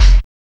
MEAN NOISY.wav